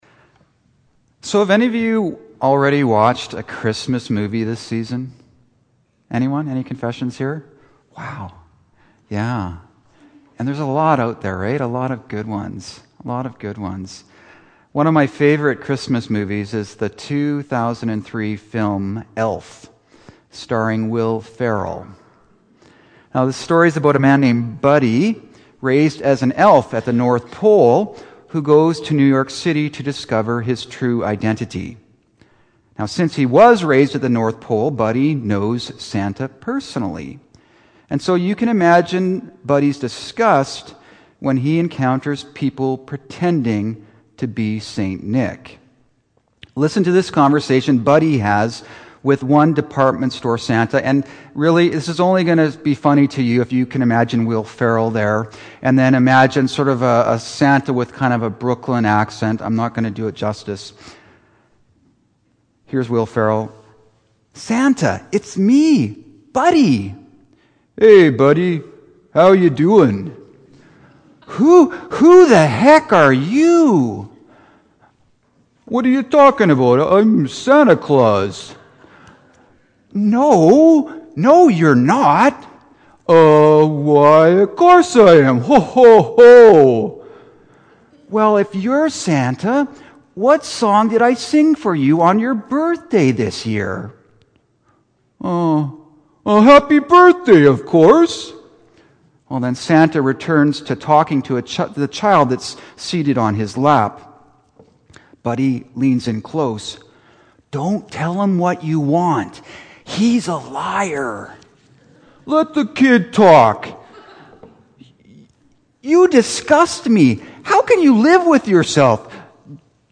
Services from St. Andrew's | St. Andrew’s Presbyterian Church